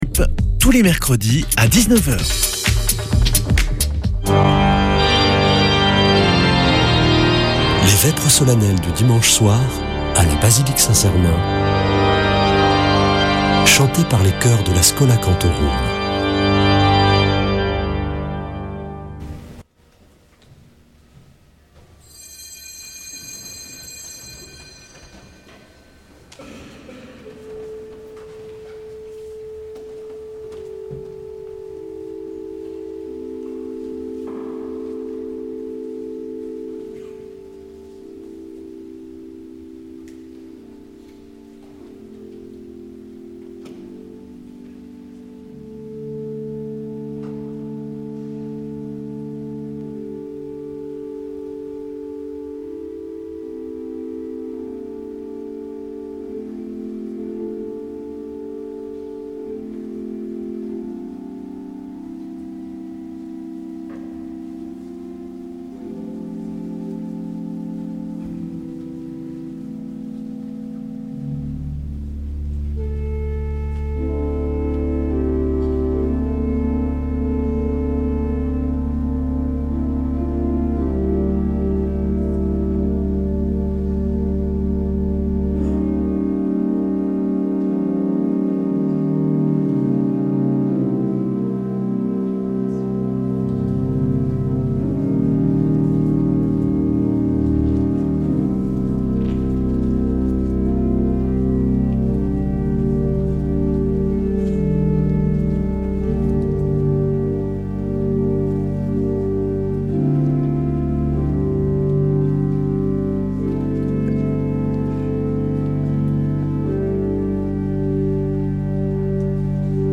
Vêpres de Saint Sernin du 26 avr.
Une émission présentée par Schola Saint Sernin Chanteurs